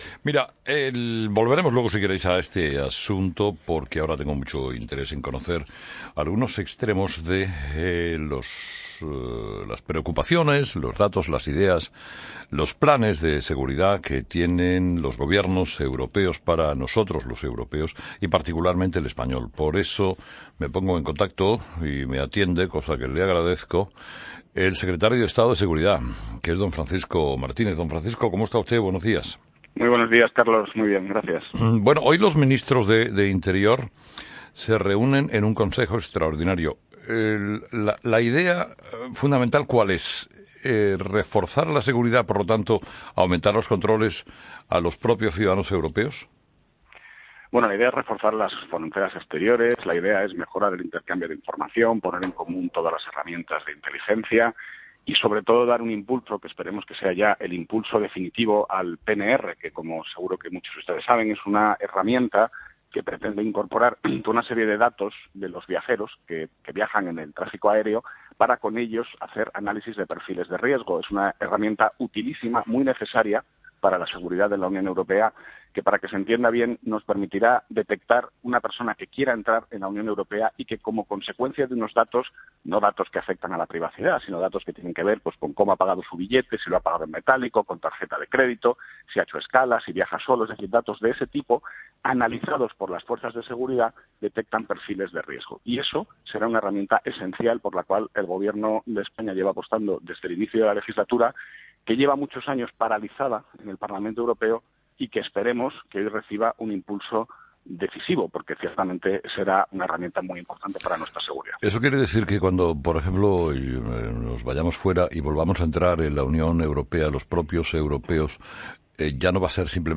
Entrevista a Francisco Martínez COPE
El secretario de Estado de Seguridad, Francisco Martínez, explica en 'Herrera en COPE' cómo será la reunión de los ministros del Interior de la UE.